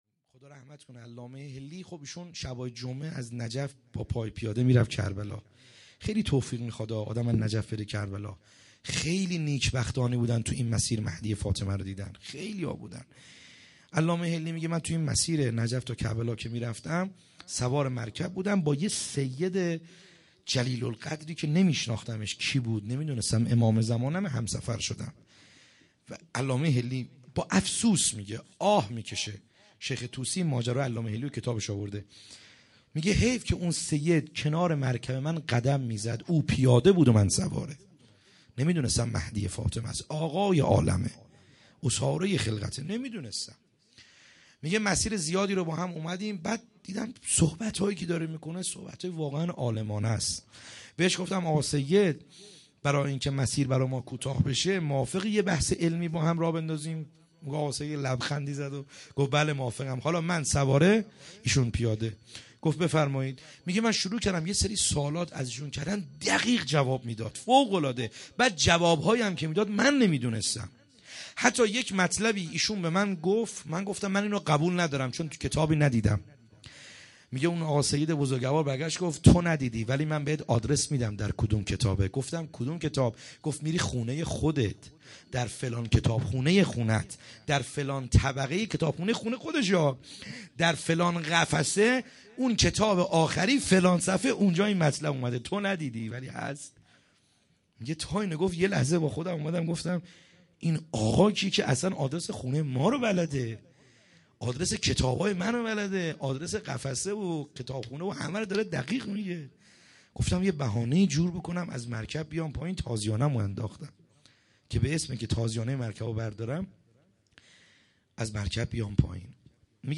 خیمه گاه - بیرق معظم محبین حضرت صاحب الزمان(عج) - روضه | مناجات با امام زمان عج